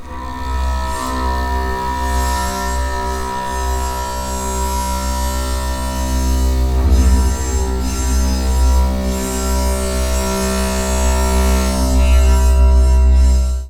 tarbass
La tessiture du tarbass couvre plus de cinq octaves de portée, réunissant celle du violoncelle et de la contrebasse.
Ils sont amovibles de façon à pouvoir modifier à volonté l’acoustique de l’instrument.
Tar-bass.mp3